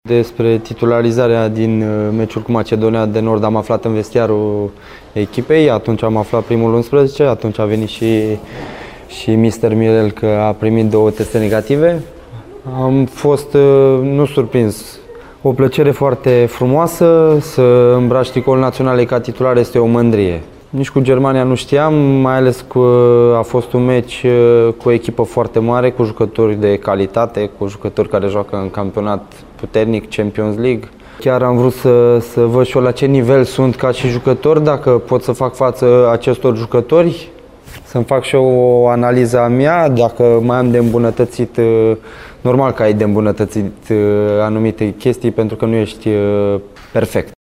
Reșițeanul Ovidiu Popescu a fost titular de două ori, la prima sa convocare la naționala mare. Folosit pe post de fundaș dreapta, jucătorul de la FCSB a povestit despre cum s-a simtiț în primul 11, atât împotriva Macedoniei de Nord, cât și a Germaniei.